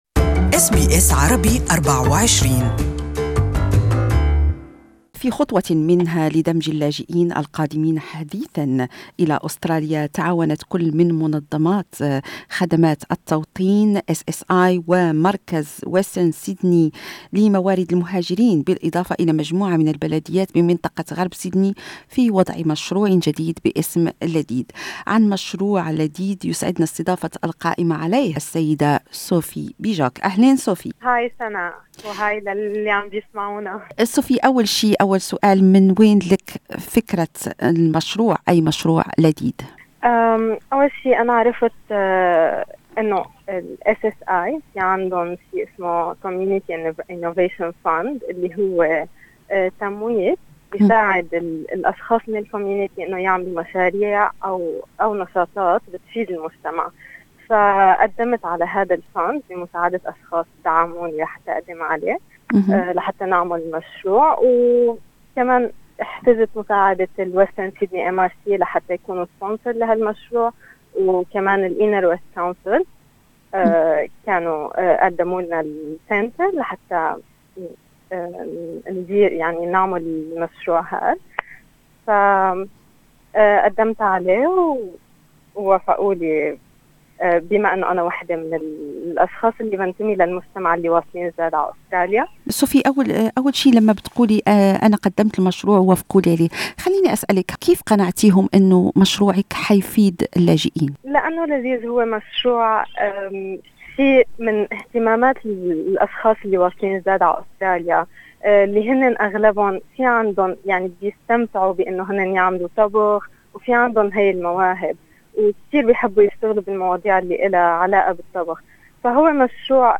This interview is in Arabic.